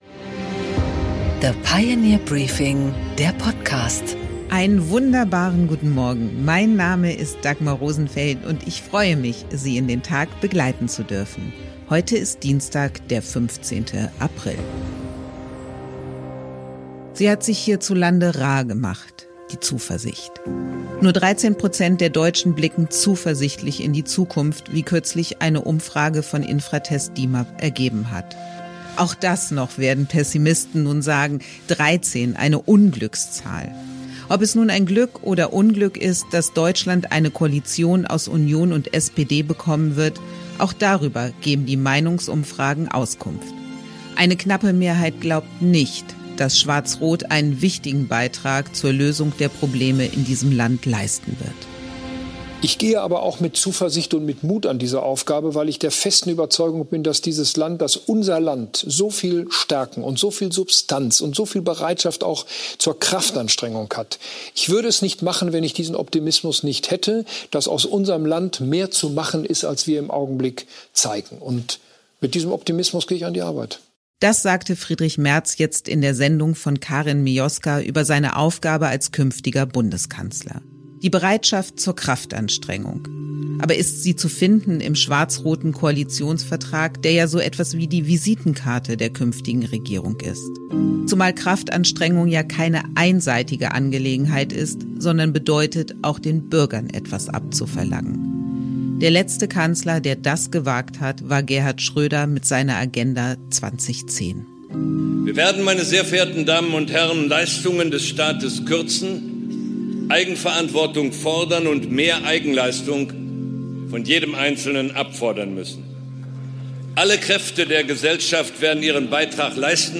Im Interview
Interview mit Bärbel Bas